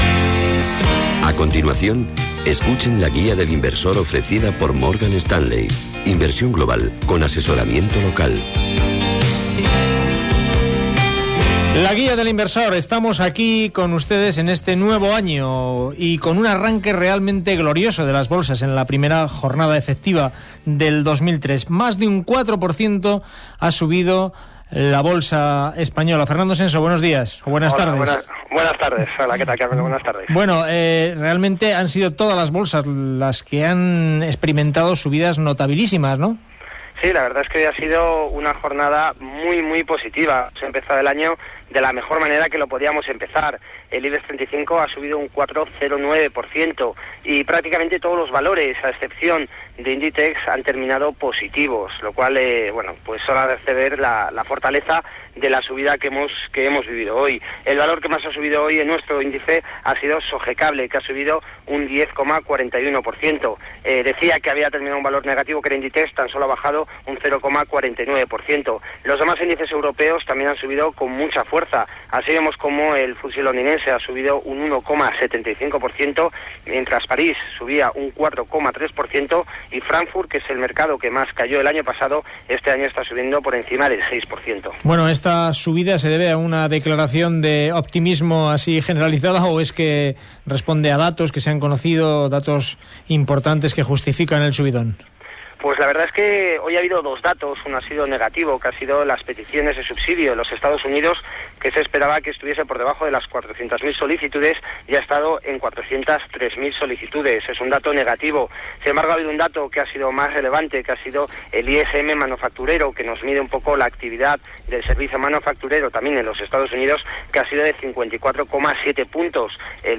Careta del programa, presentació, situació de les borses econòmiques, publicitat
Informatiu